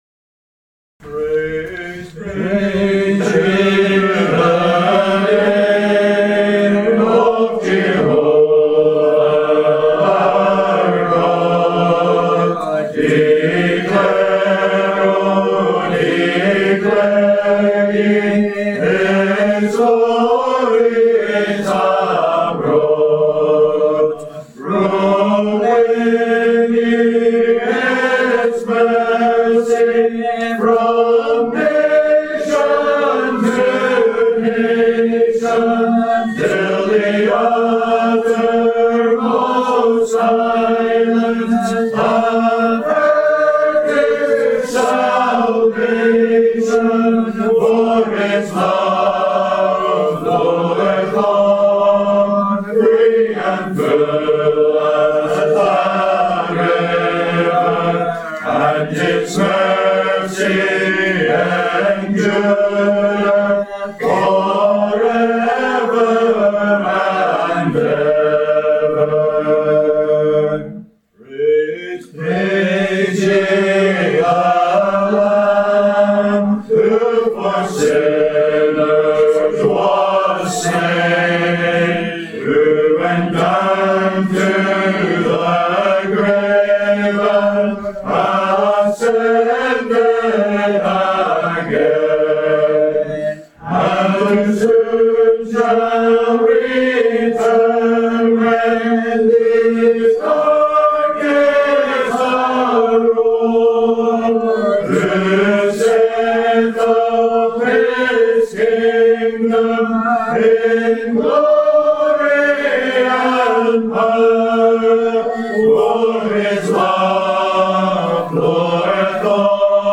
Annual Conference 2025
hhgh-2025-hymns.mp3